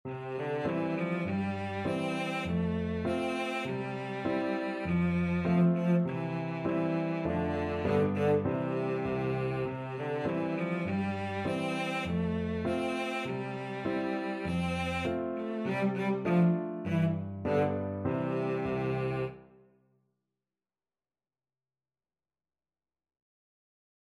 Cello version
Childrens
Traditional Music of unknown author.
4/4 (View more 4/4 Music)
Moderato
C4-C5